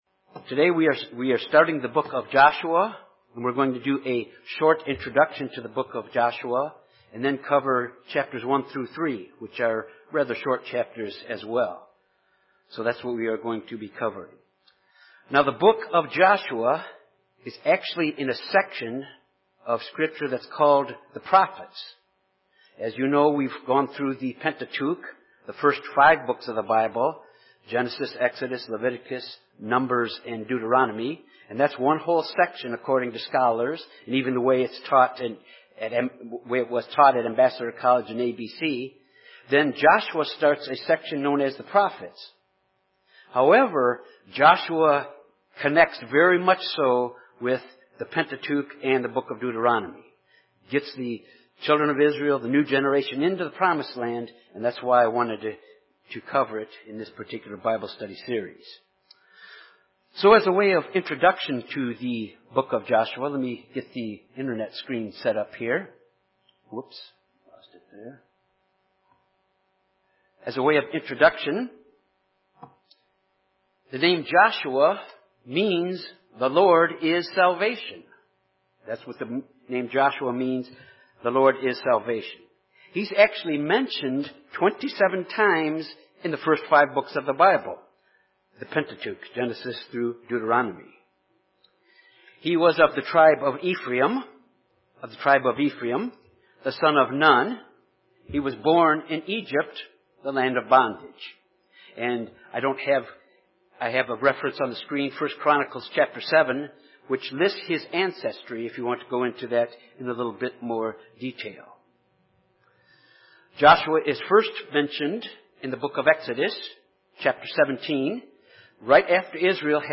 This Bible study introduces the book of Joshua and God’s commission of Joshua to replace Moses to lead the children of Israel into the Promised Land. It covers the spying out of Jericho and the crossing of Jordan into the Promised Land.